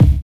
Wu-RZA-Kick 54.wav